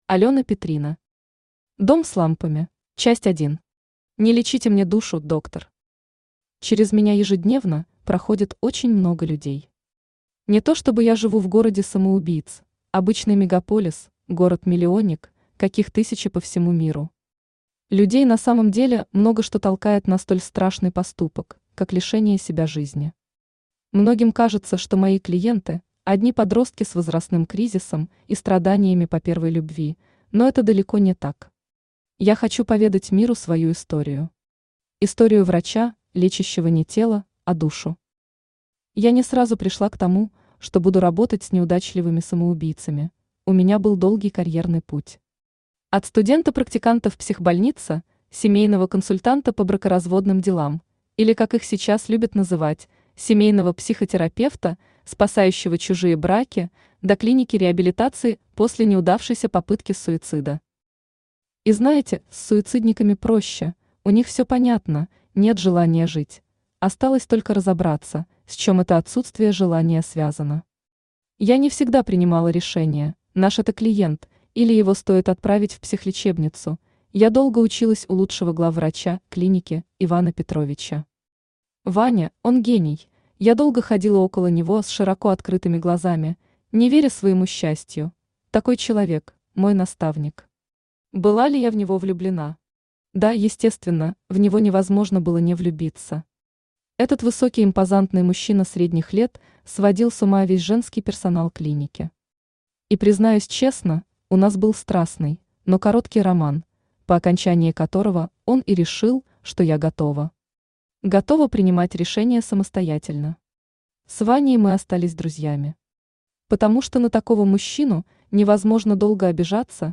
Aудиокнига Дом с лампами Автор Алёна Сергеевна Петрина Читает аудиокнигу Авточтец ЛитРес.